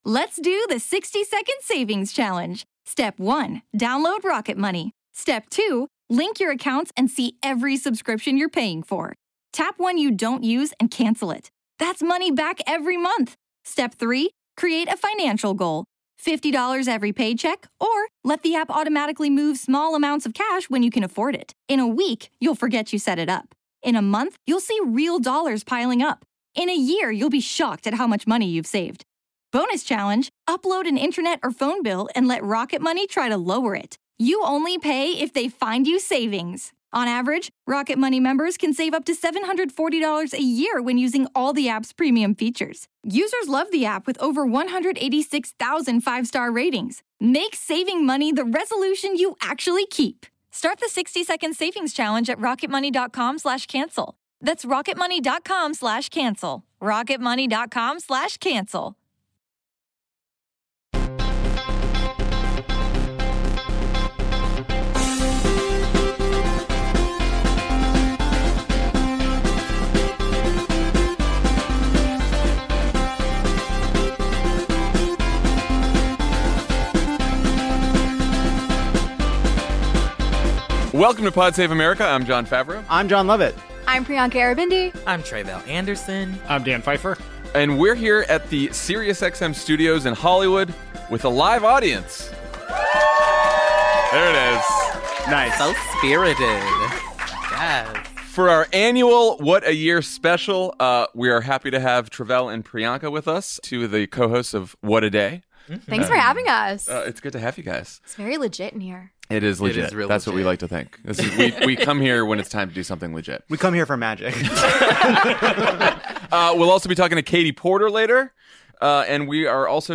Plus, Representative Katie Porter stops by the studio, and Jon, Jon, and Dan decide the worst Take of the Year in the Pundies Awards.